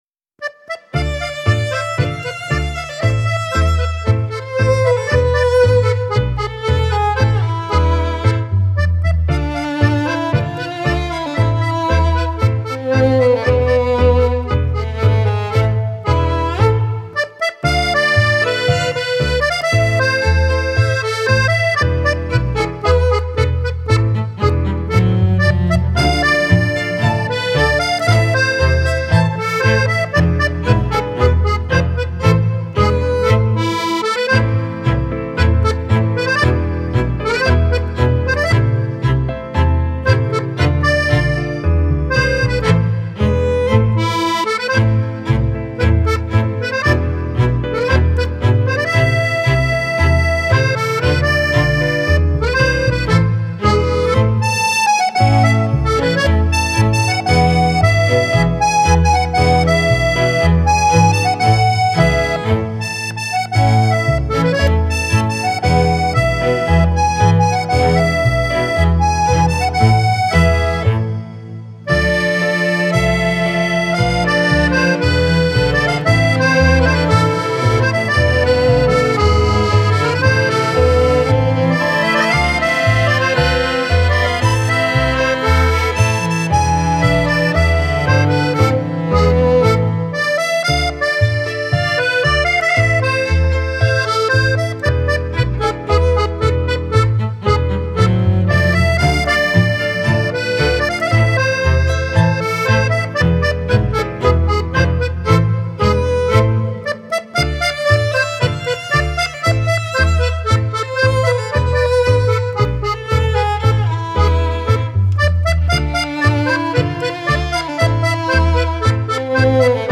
Okul Zil Sesleri 4
Birçok müzik çeşitleri bir araya getirilerek hazırlanmıştır.